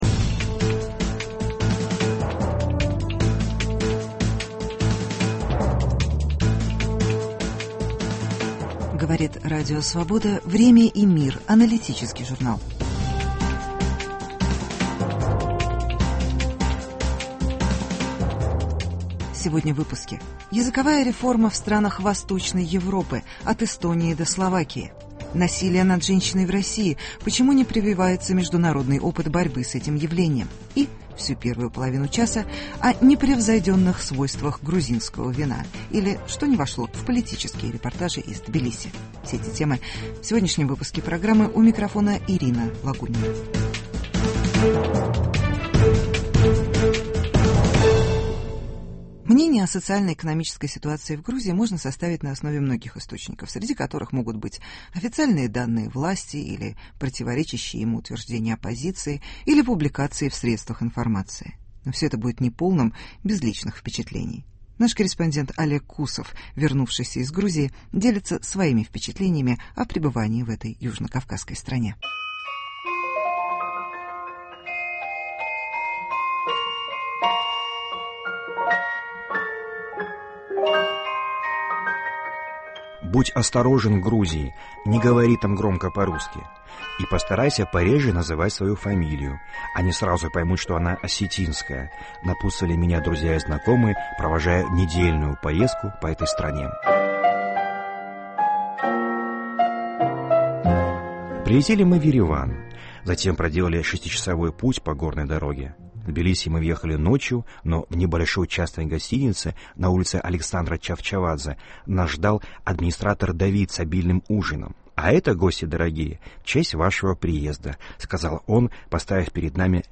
Репортаж с грузинской улицы, ч 2. Языковые реформы в странах Восточной Европы.